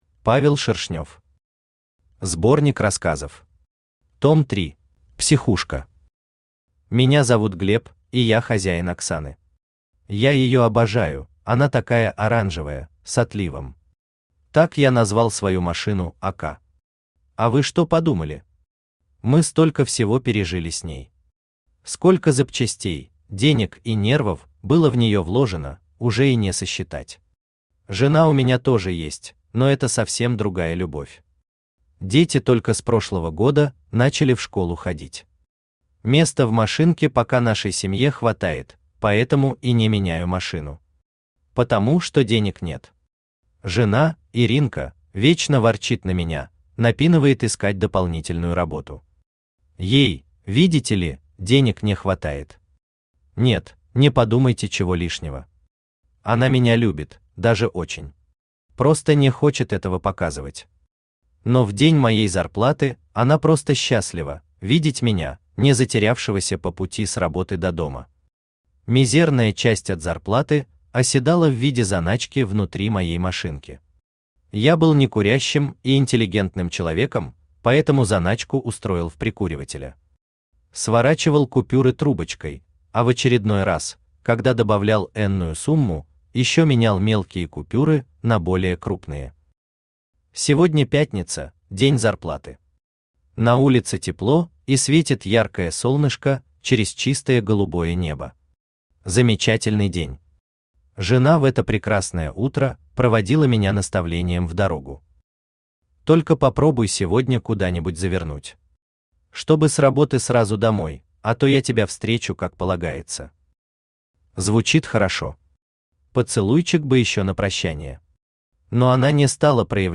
Аудиокнига Сборник рассказов. Том 3 | Библиотека аудиокниг
Том 3 Автор Павел Валерьевич Шершнёв Читает аудиокнигу Авточтец ЛитРес.